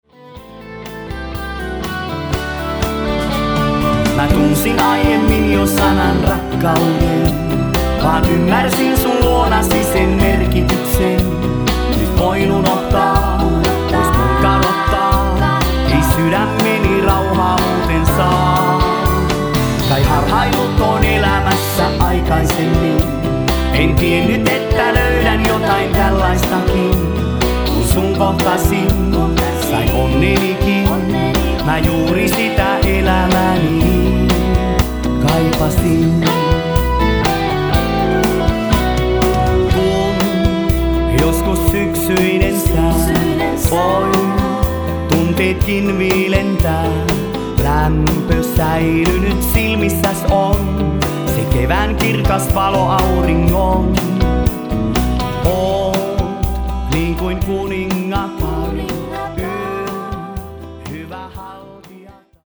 Iskelmä musiikkia moneen makuun sisältävä